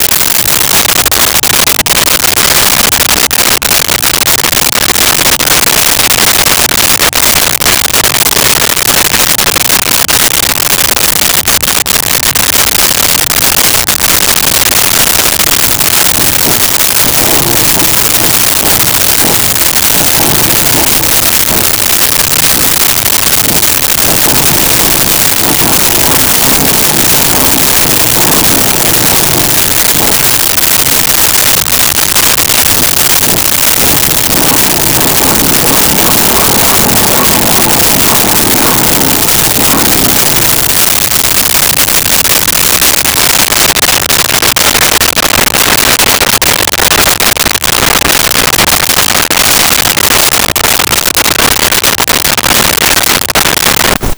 Muscle Car Start Fast Revs Off
Muscle Car Start Fast Revs Off.wav